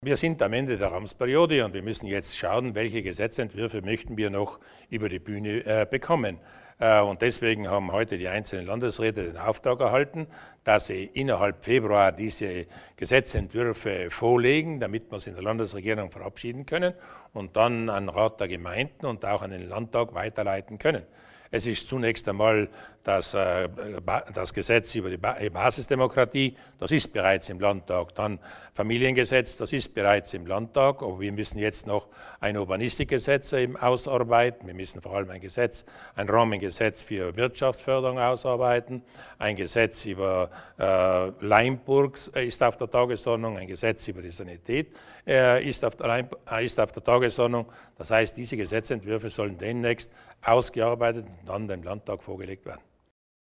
Landeshauptmann Durnwalder über die Prioritäten der nächsten sechs Monate